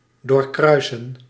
Ääntäminen
IPA: /ˌdoːrˈkrœy̯sə(n)/